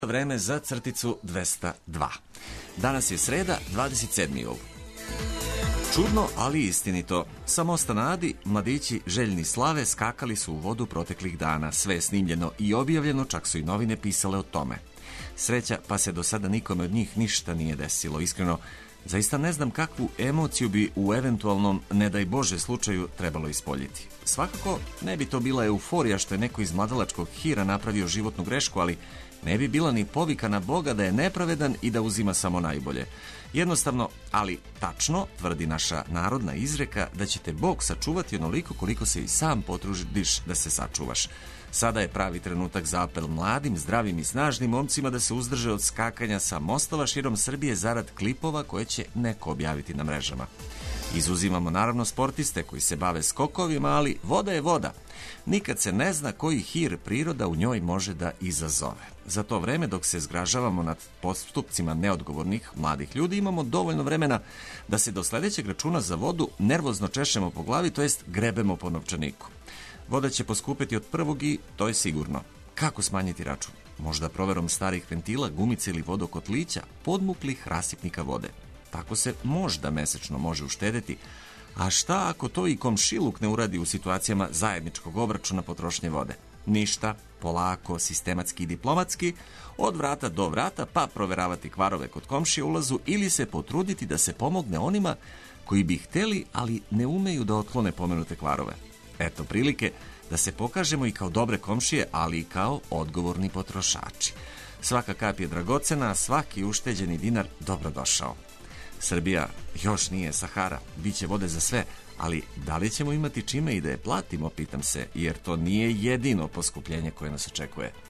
Овога јутра будимо се уз много веселих песама и занимљивих тема из живота. Како победити комарце у Београду?